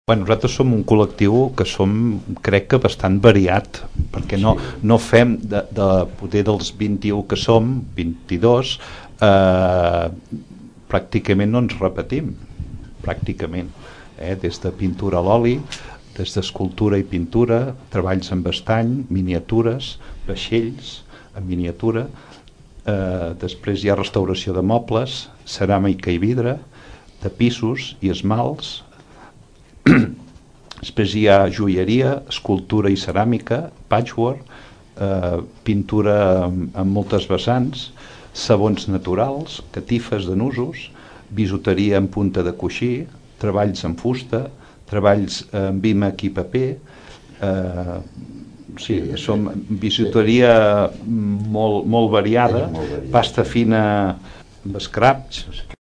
Un membre del col·lectiu, ens recorda quines tècniques i labors treballen els artesans torderencs.